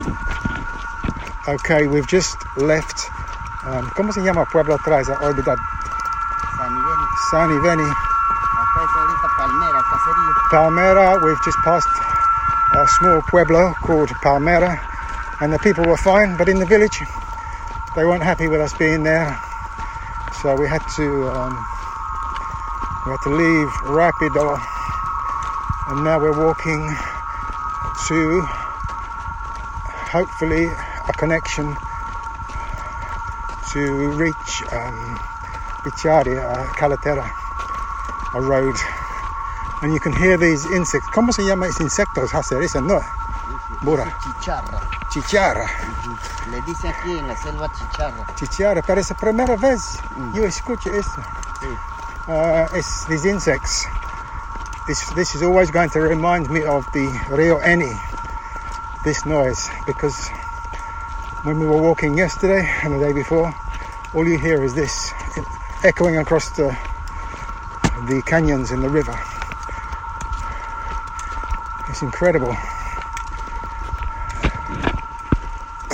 Ene Insectos
Ene-Insectos-Mp3.mp3